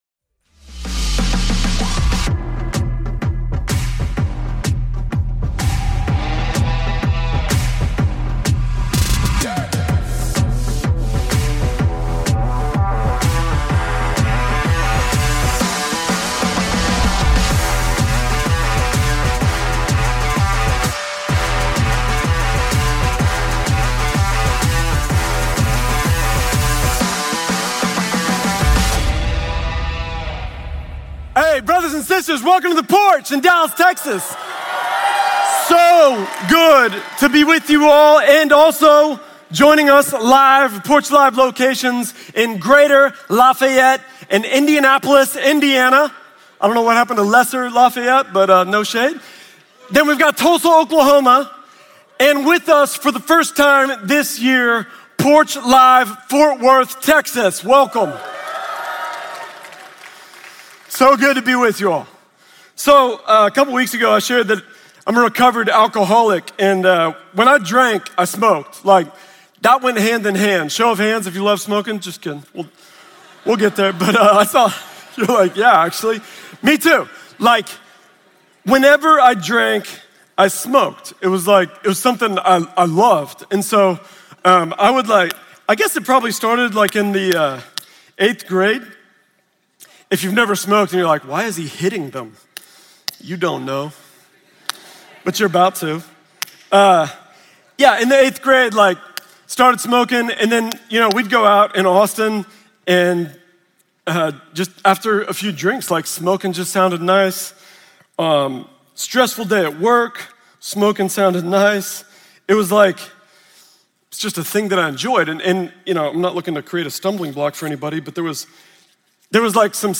Religion & Spirituality, Christianity